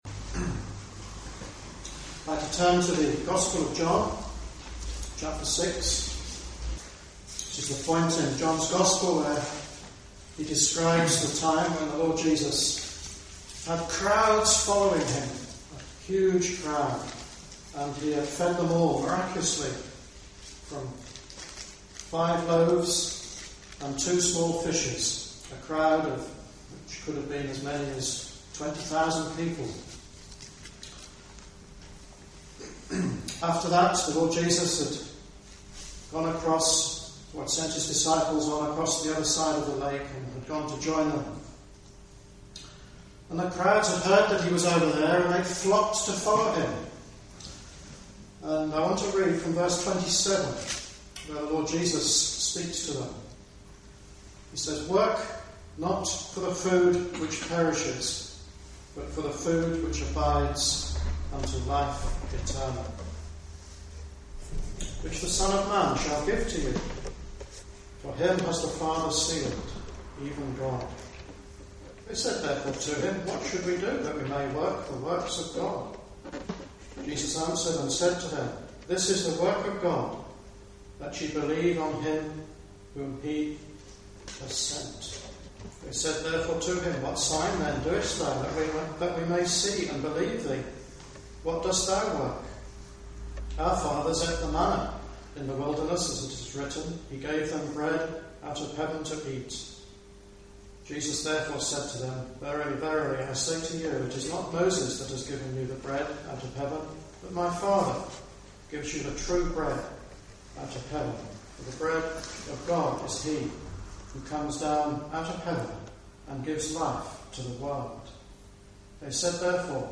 In this Gospel preaching, you will hear of the Christian Way and what it means to trust in Jesus as Saviour and Lord.